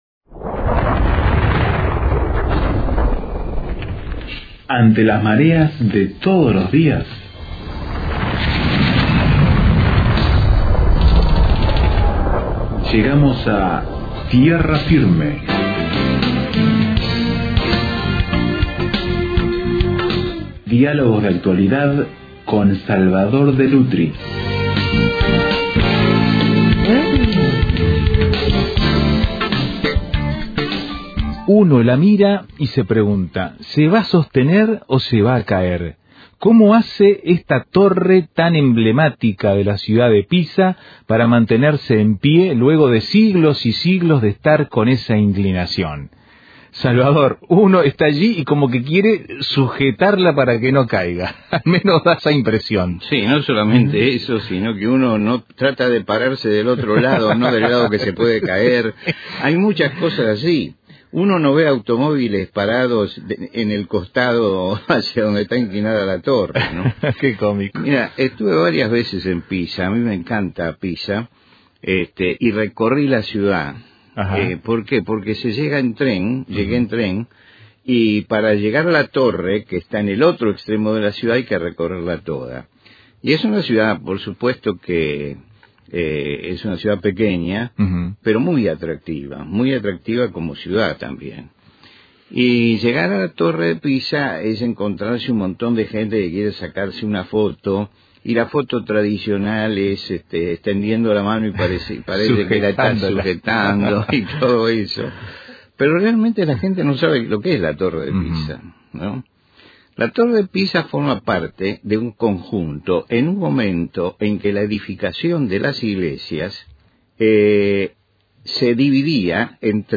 Construcciones emblemáticas si las hay es la que traemos a la charla en esta ocasión en Tierra Firme.